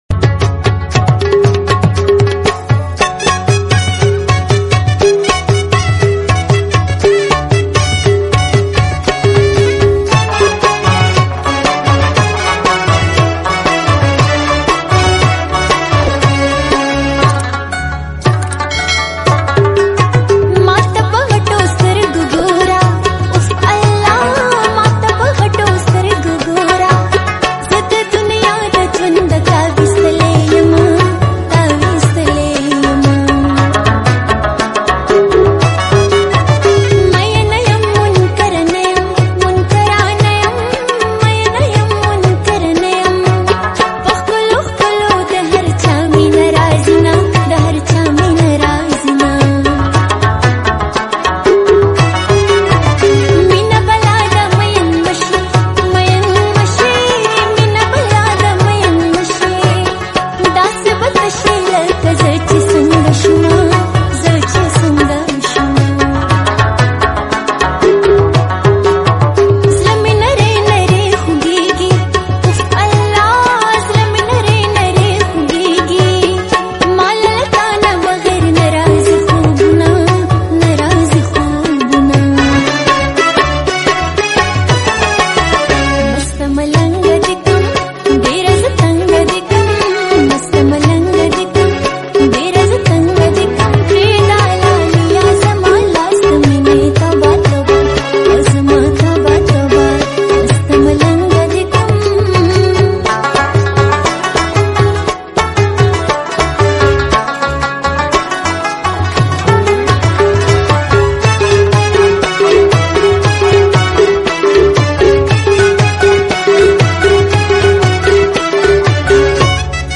Pashto Best Song | Slowed and Reverb